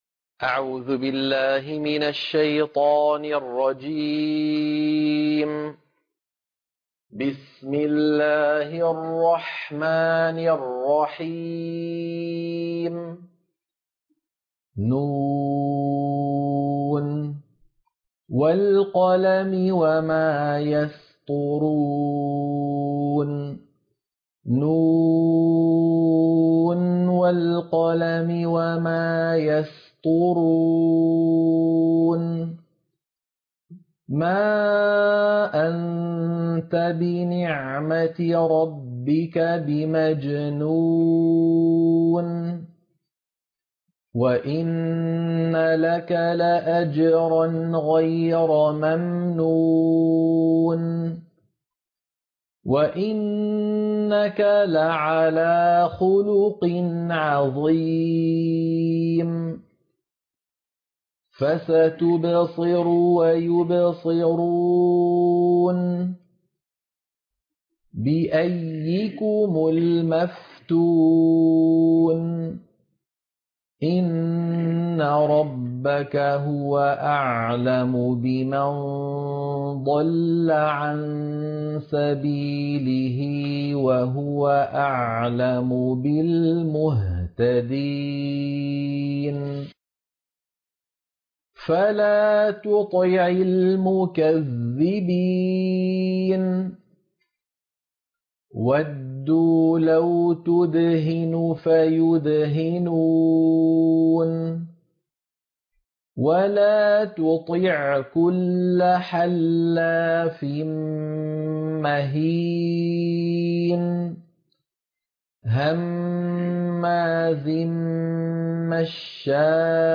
سورة القلم - القراءة المنهجية - الشيخ أيمن سويد